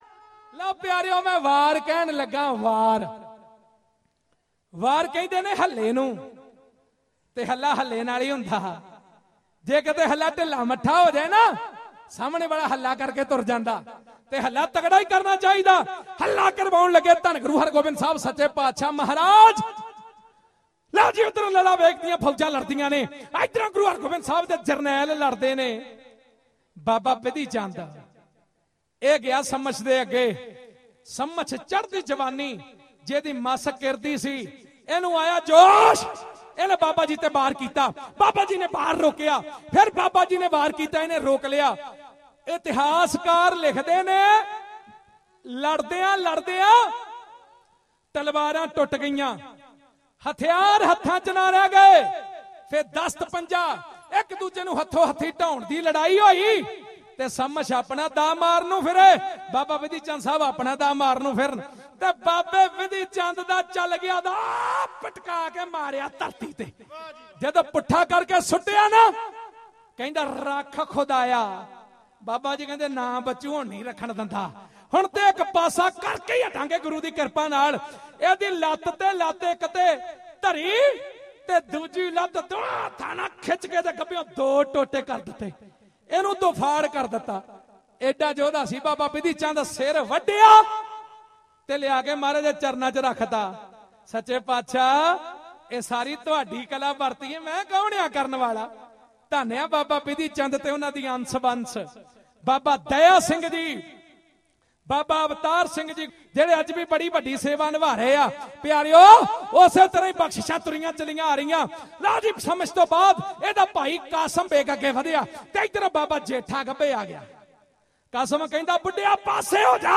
Genre: Dhadi Varan